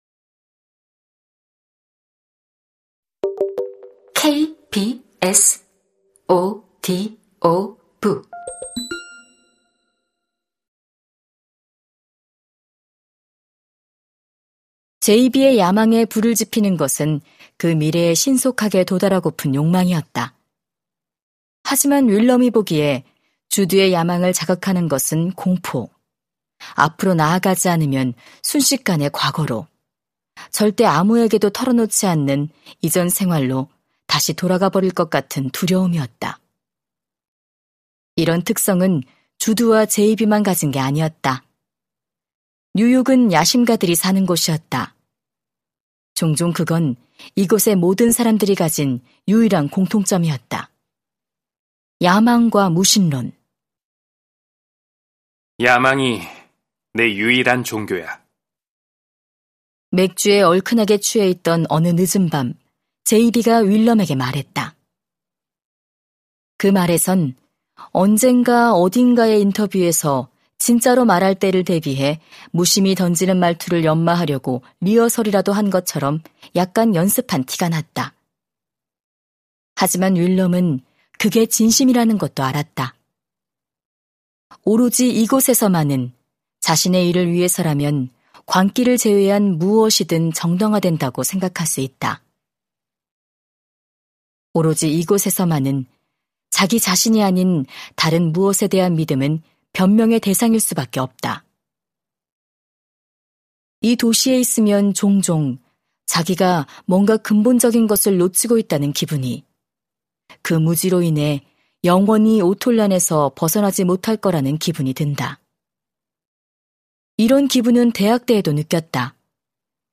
KBS오디오북_리틀 라이프_한야 야나기하라_성우